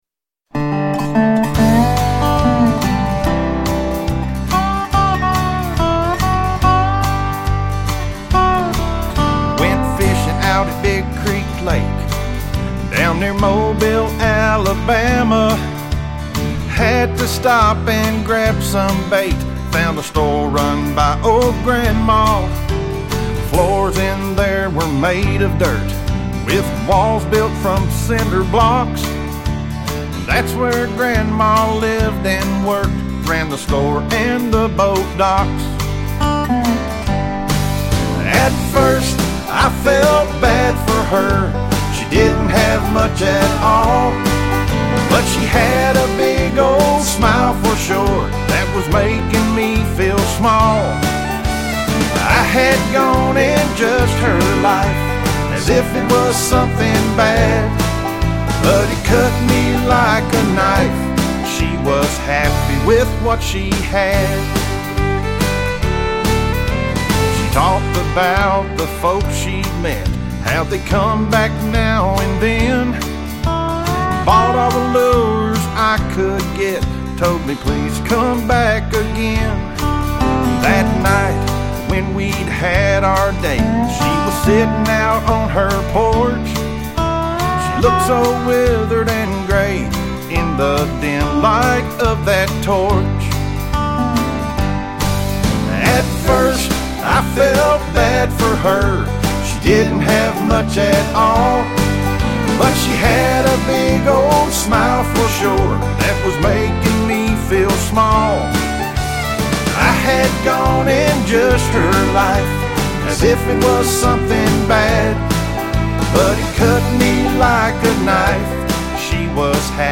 Complete Demo Song, with lyrics and music: